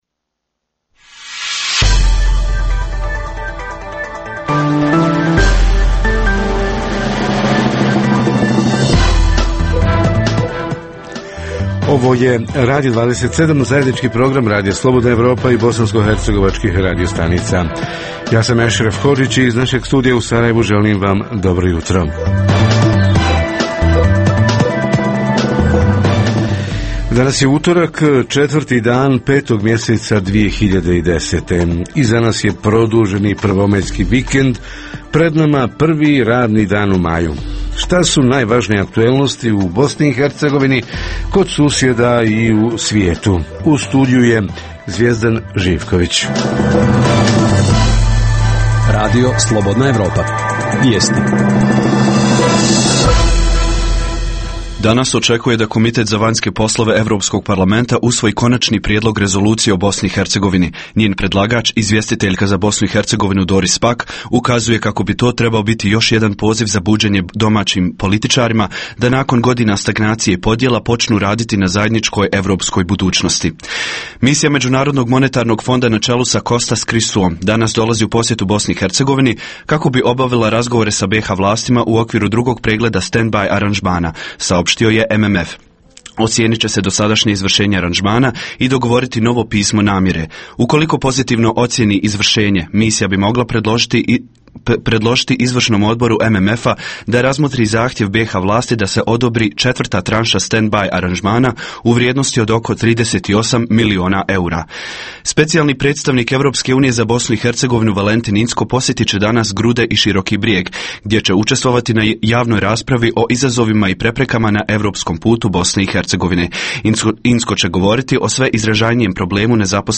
U posjeti onima koji – iako je praznik rada – rade (ljekari /hitna pomoć, porodilište i sl./, vatrogasci, policija, dežurni u nekom komunalnom preduzeću /elektrodistribucija, vodovod i sl./ Reporteri iz cijele BiH javljaju o najaktuelnijim događajima u njihovim sredinama. Redovna rubrika Radija 27 utorkom je "Svijet interneta". Redovni sadržaji jutarnjeg programa za BiH su i vijesti i muzika.